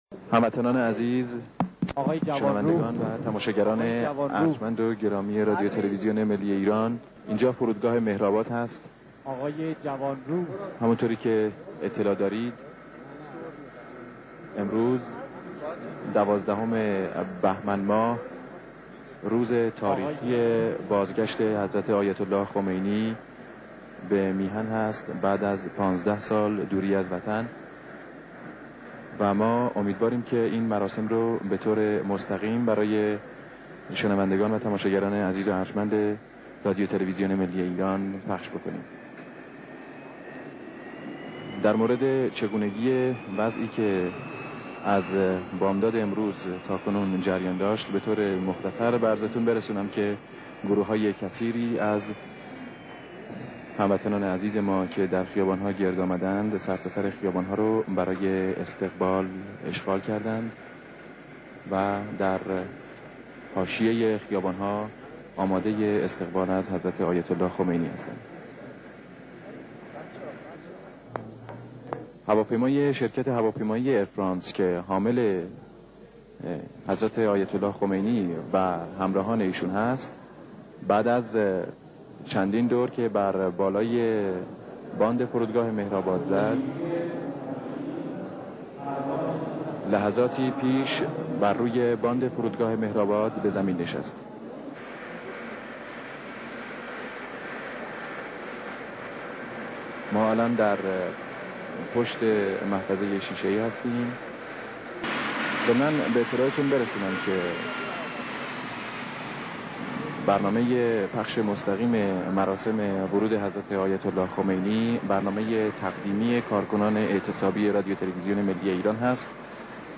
فایل صوتی منتشر نشده از صدای تلویزیون ملی در هنگام ورود امام خمینی (ره) به داخل كشور